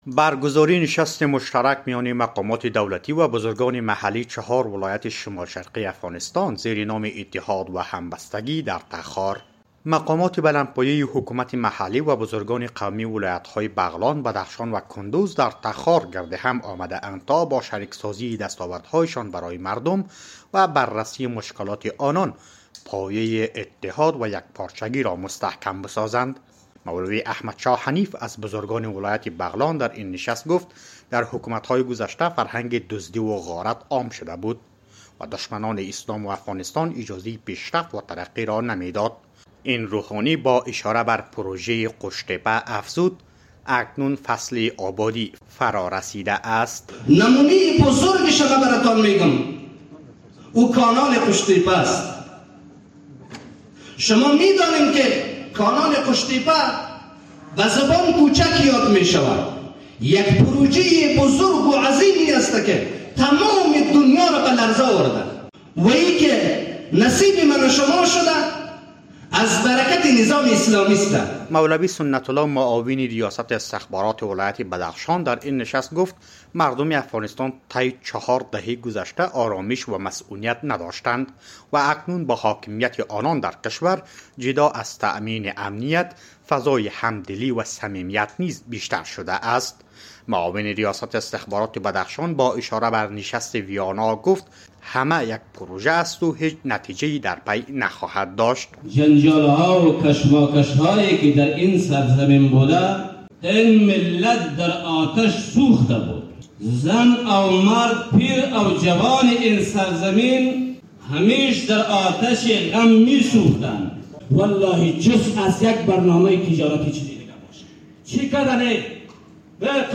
نشست مشترک مقامات دولتی و بزرگان محلی چهار ولایت شمال‌شرقی افغانستان زیر نام «اتحاد و همبستگی» در تخار برگزار شد.